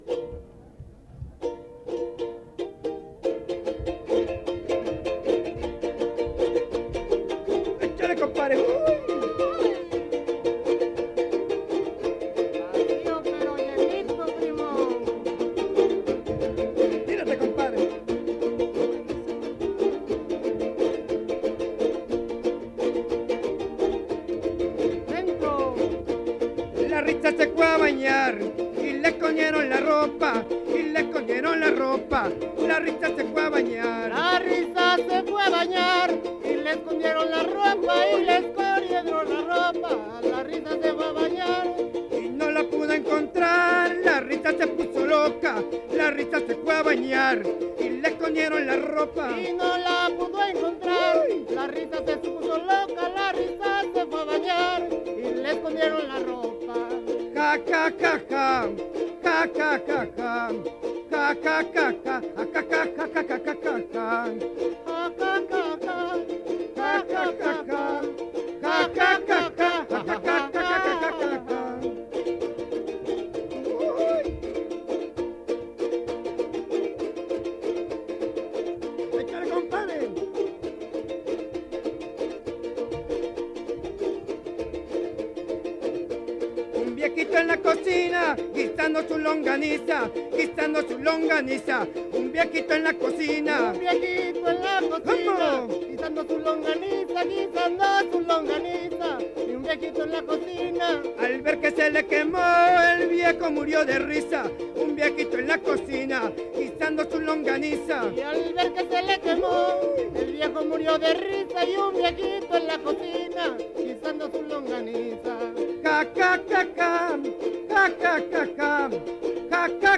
Encuentro de jaraneros